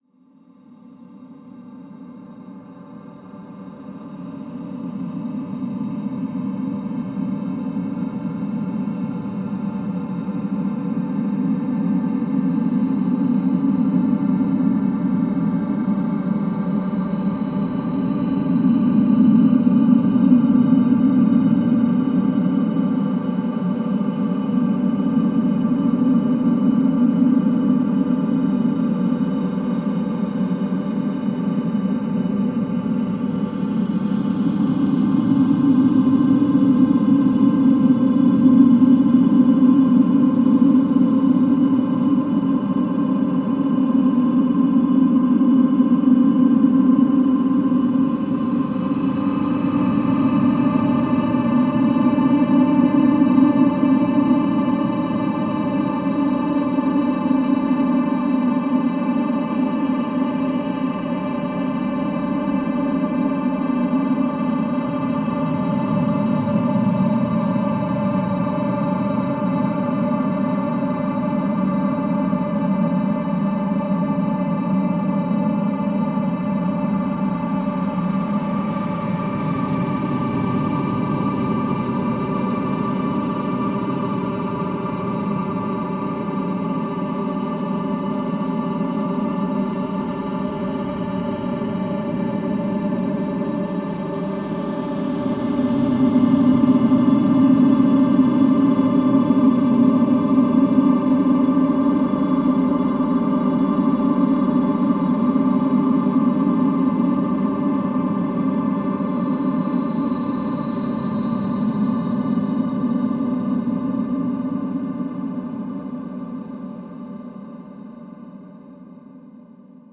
If you’re interested I paul stretched 1 bar of your sample, so like 1.3 seconds.